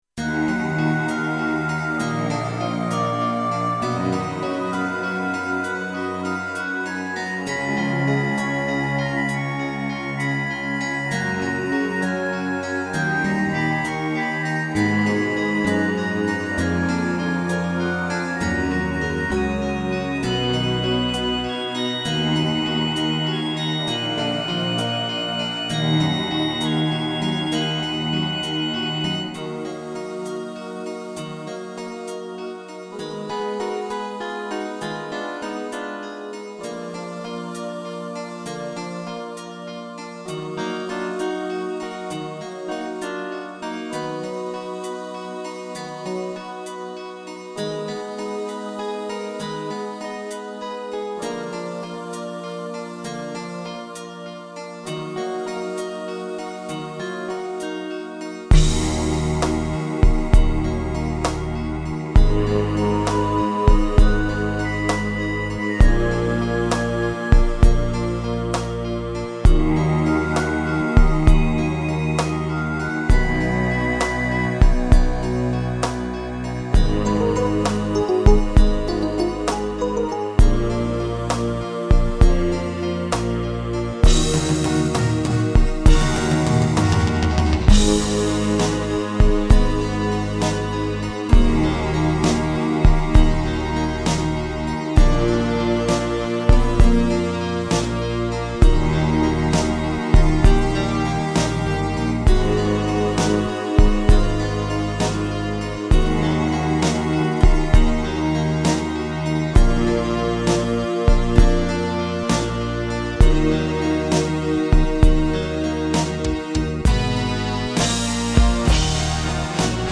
минусовка версия 51393